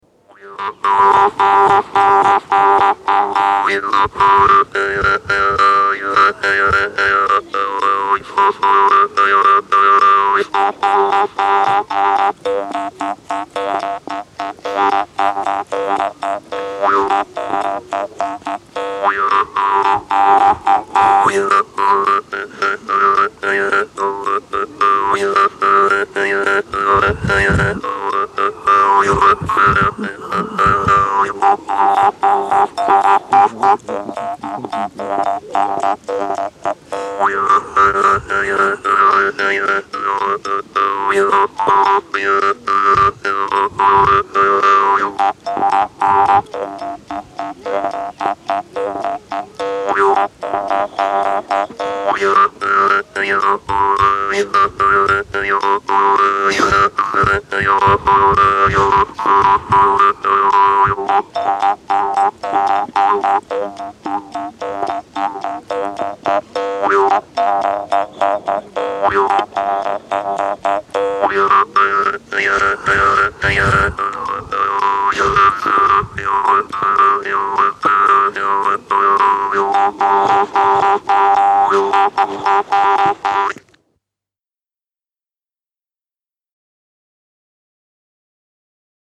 Música mapuche
Música tradicional
Folklore
Música vocal